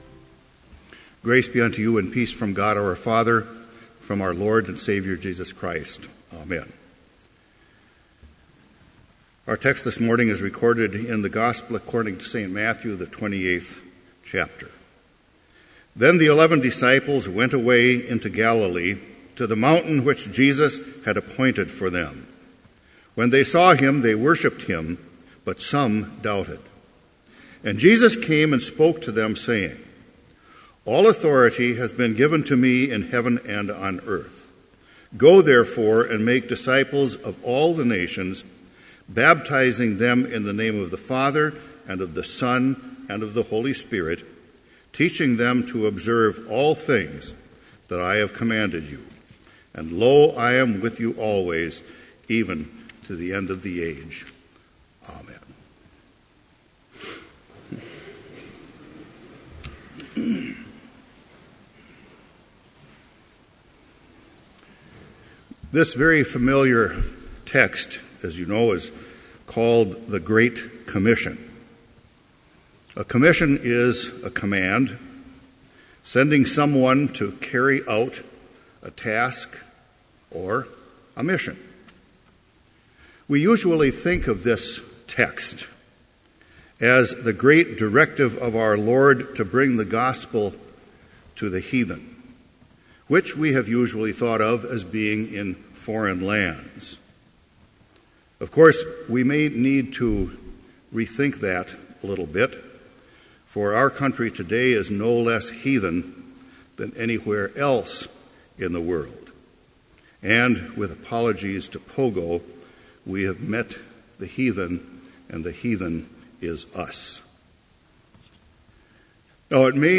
Complete service audio for Chapel - January 27, 2015